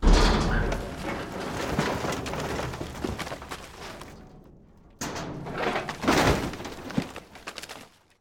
waste_container_0.ogg